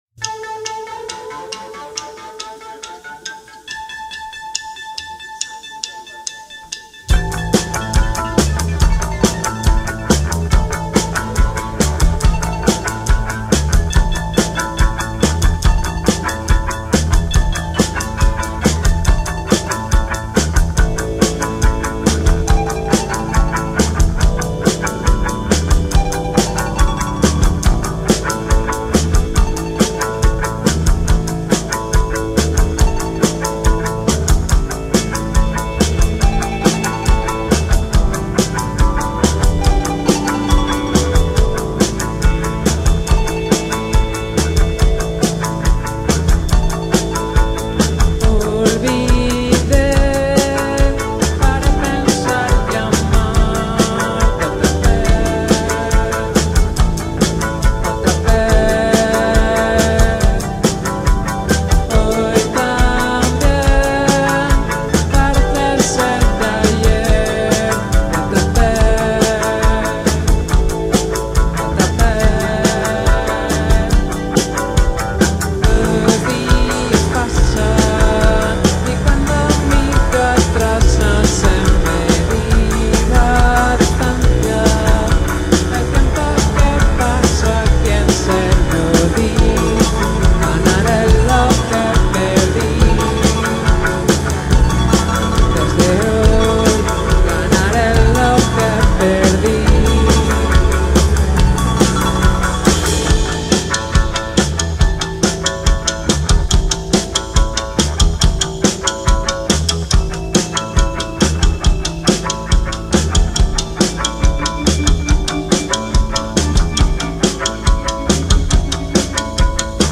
recorded during Les Vincelles Festival 2024
guitar, vocals
bass
keyboards
drums
Indie pop quartet